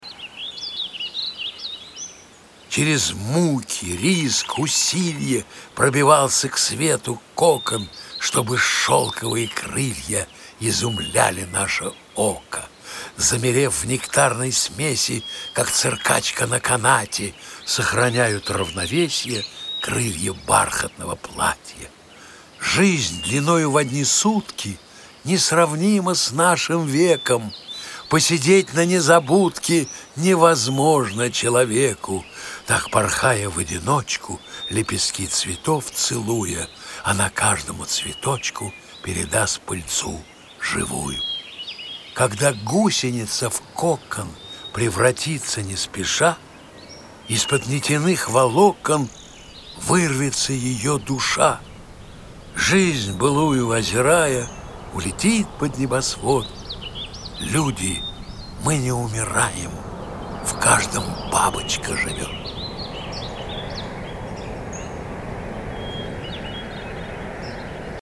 Gaft-Babochka-chitaet-avtor-stih-club-ru.mp3